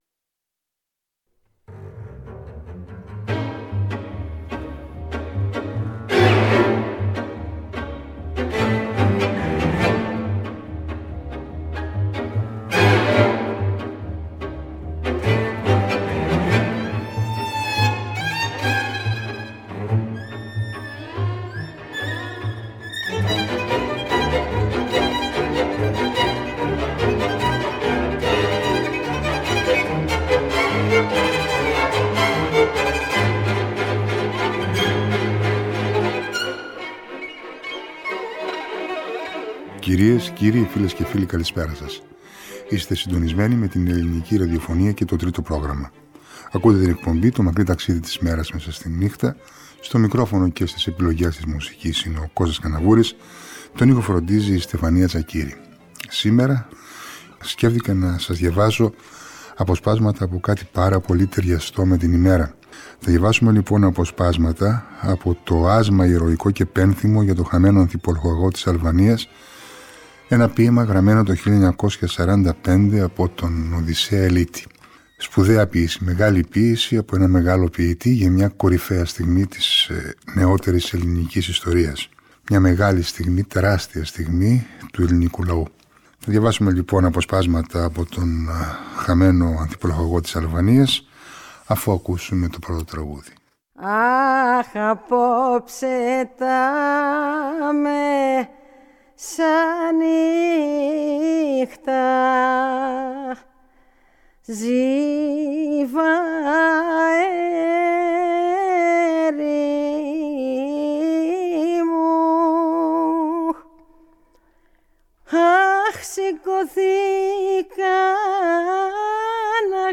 διαβάζει αποσπάσματα ενός από τα πιο εμβληματικά έργα της ελληνικής ποίησης